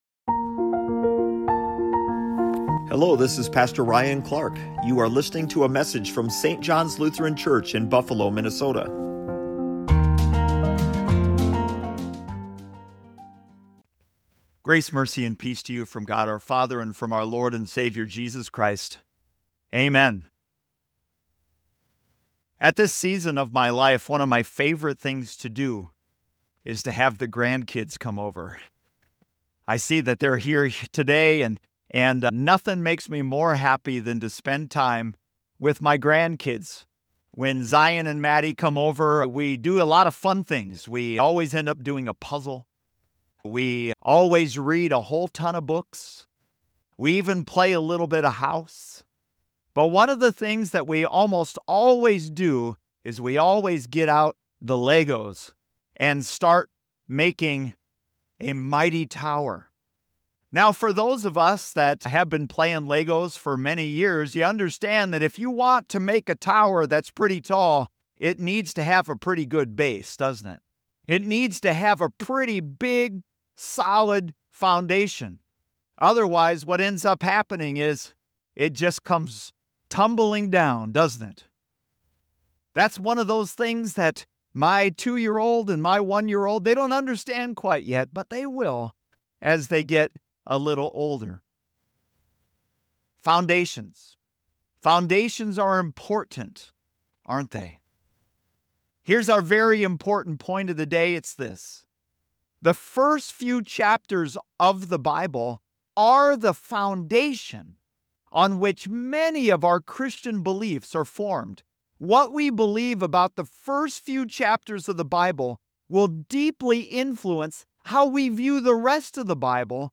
Grab your Bible and join us as we begin our two year sermon series "cover to cover" and turn to the very beginning, the book of Genesis.